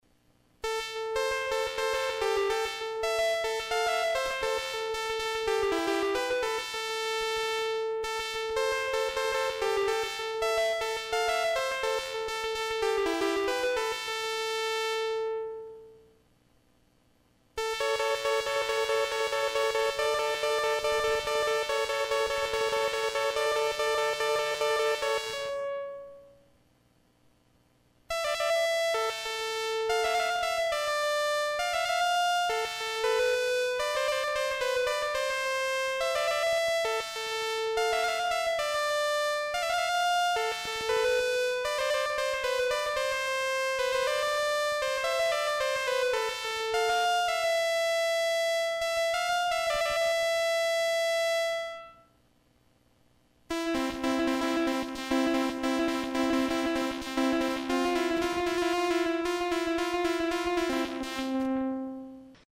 Analog DELAY / ECHO / CHORUS / VIBRATO
Testes utilizando um Syntyetizador CS-10 Yamanha
REVERBERATION
reverberation.mp3